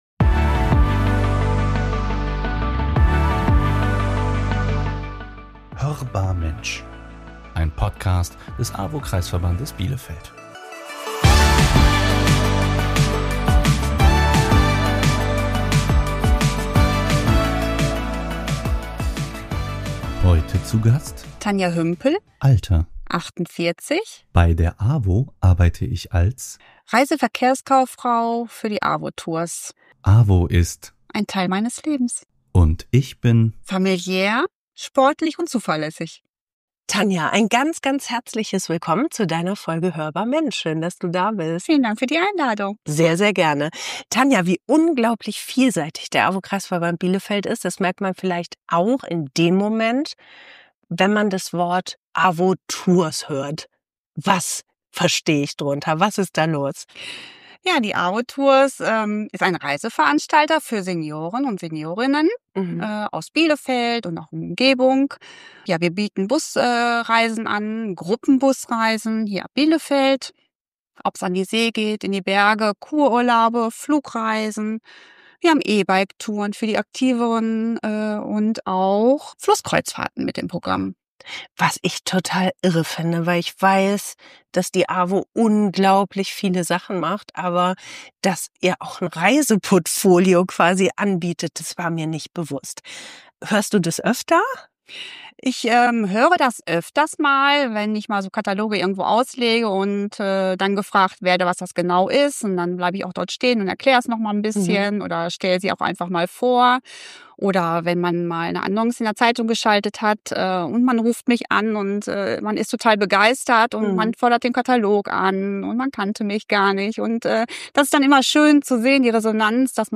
Und im Gespräch wird klar: Der AWO Kreisverband Bielefeld ist unglaublich vielfältig – er hat nämlich sogar ein eigenes „Reisebüro“.